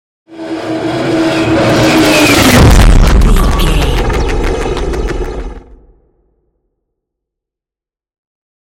Scifi passby whoosh long
Sound Effects
Atonal
futuristic
intense
pass by